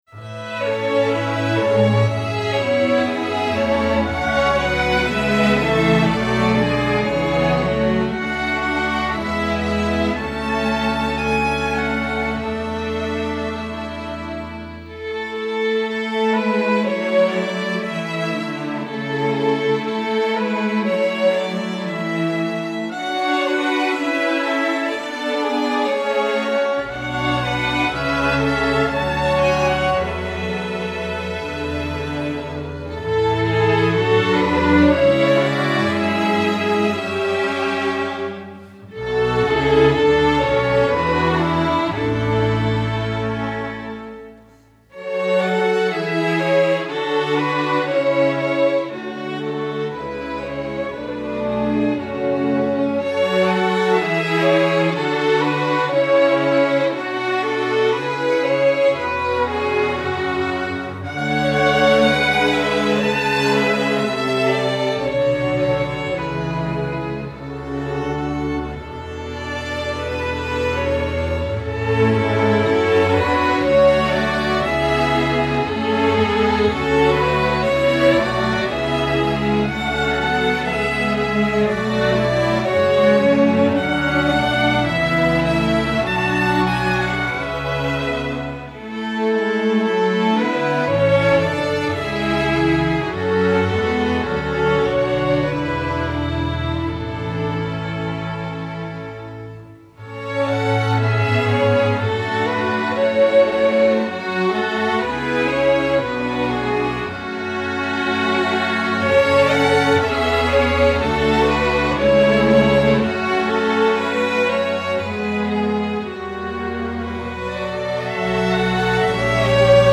sacred